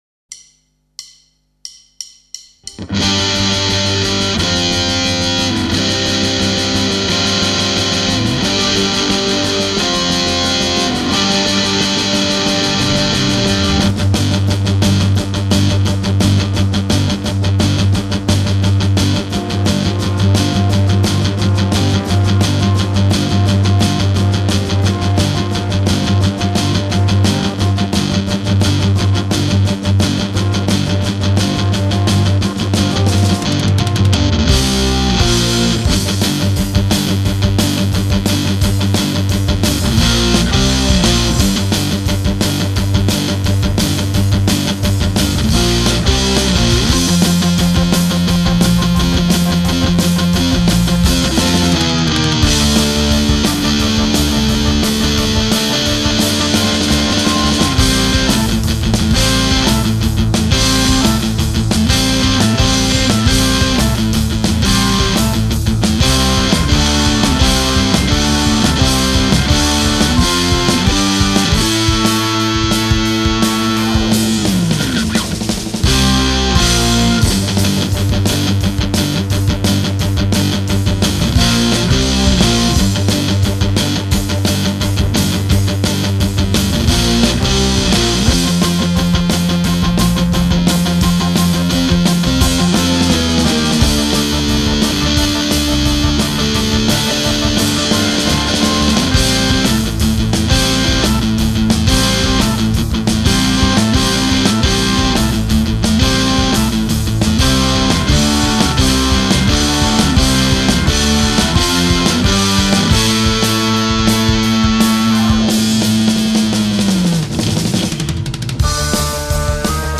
- erreurs grossières de rythme
- manque des parties rythmique de guitare
- mixage attroce